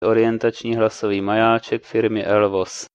Orientační hlasový majáček (OHM)
Obrázek Orientačního hlasového majáčku typu OHM1.
Třetí fráze pak může obsahovat rozšířenější popis okolí majáčku, častěji se však využívá k reprodukci přerušovaného klepání, které slouží jako akustický maják pro snazší nalezení vchodu (toto klepání se spouští z vysílačky povelem č. 3).